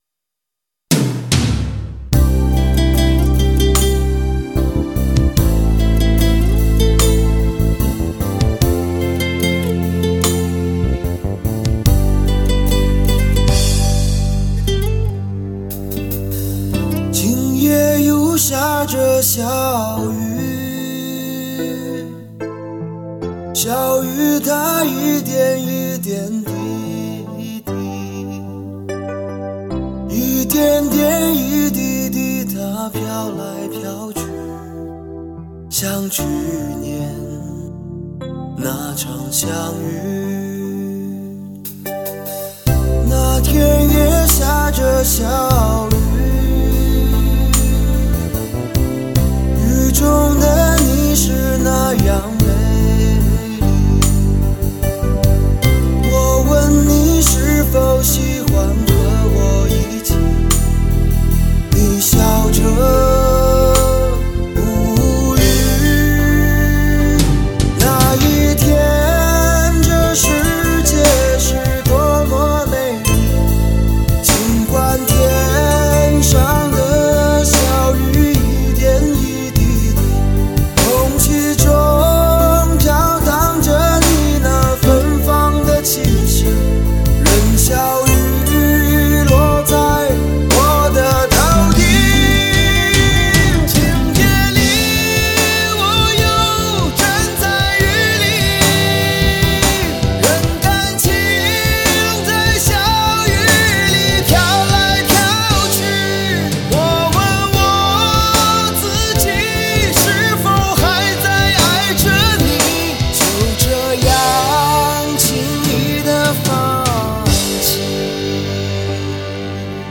国际汽车展销会指定产品，音频母带直刻，属于跨世纪领先技术全新高科技录音理念。